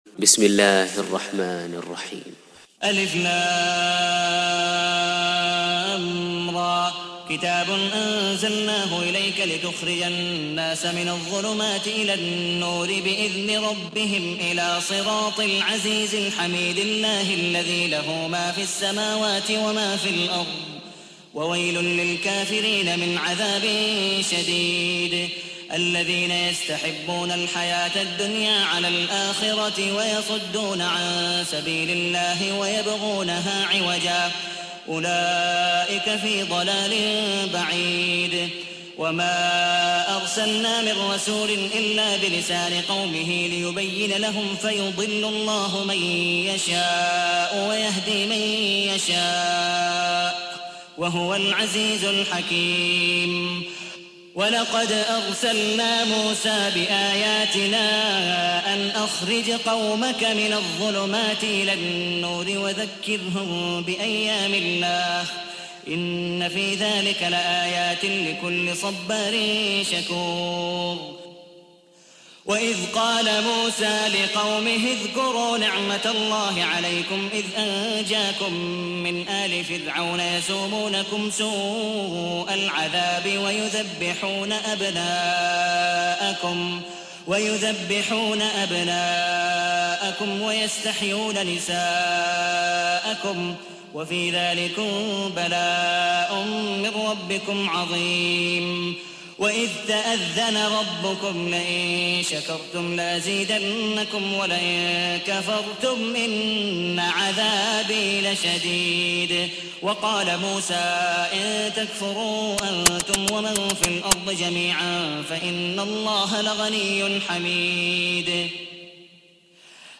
تحميل : 14. سورة إبراهيم / القارئ عبد الودود مقبول حنيف / القرآن الكريم / موقع يا حسين